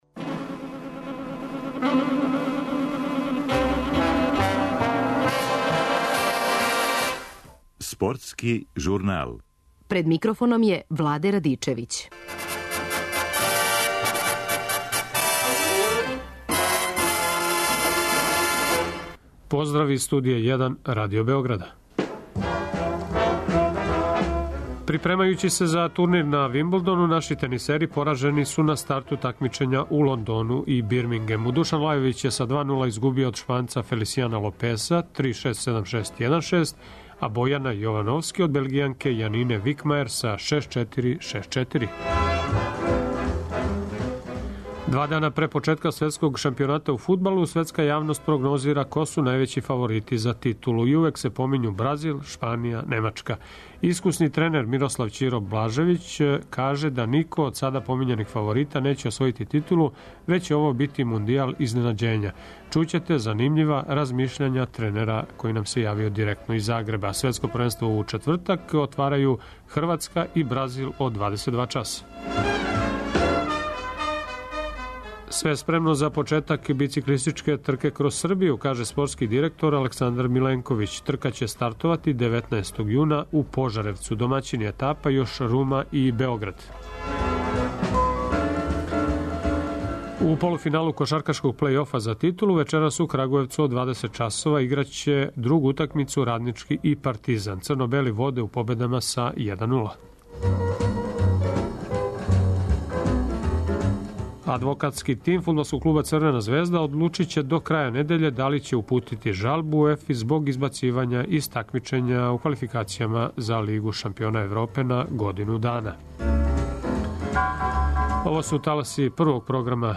О шансама Босне и Херцеговине и Хрватске говориће Драган Џајић. Такође, о мондијалу у Бразилу ексклузивно за Радио Београд говори тренер Мирослав Ћиро Блажевић.